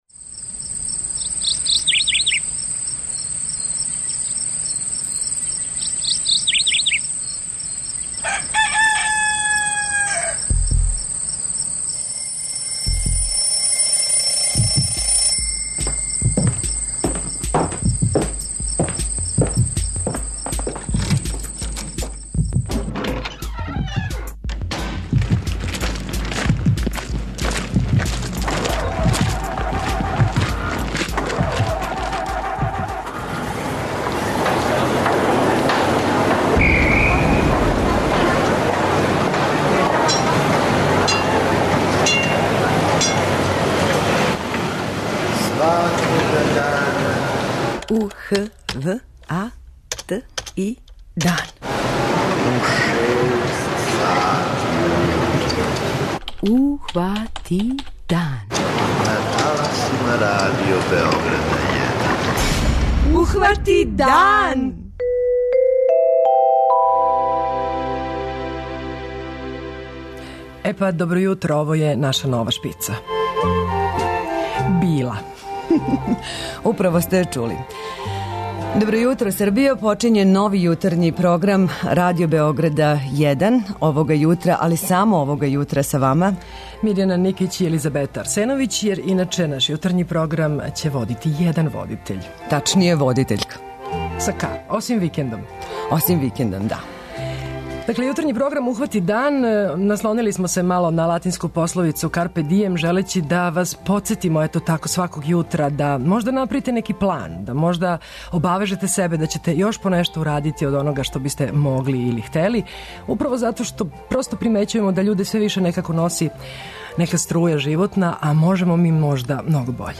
Радним данима од 6 до 9, викендом до 8 часова, са вама ће бити водитељи Радио Београда - искусни и млади, а радним данима остаће и оно на шта сте већ навикли - телевизијска укључења у 7.55 и око 8.30, када ће, на пар минута, "мали људи из радија" постајати видљиви.